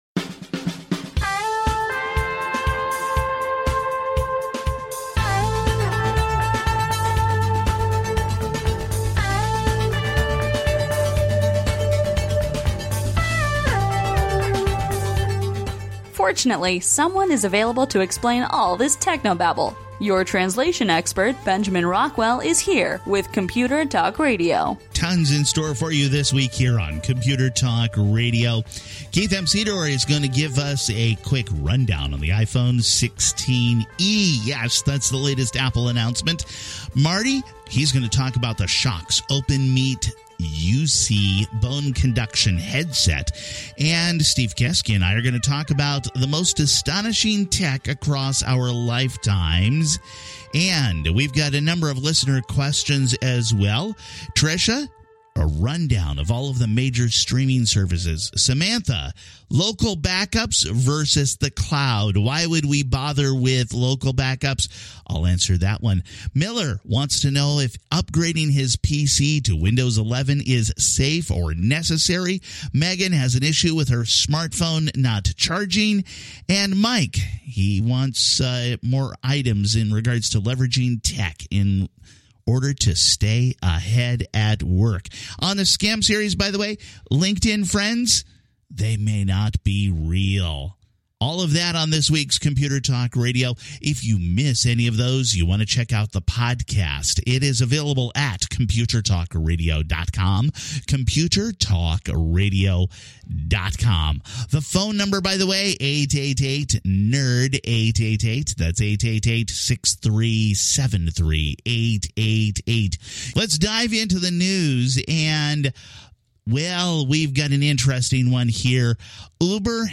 Computer Talk Radio is a nationally syndicated broadcast radio program on computers and technology, and how they impact your life.